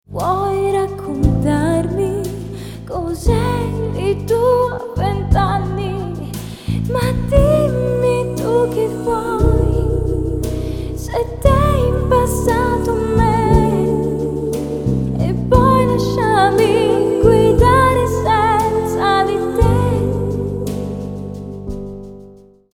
Guitars
Bass
Drums, Programming
Keyboards, Piano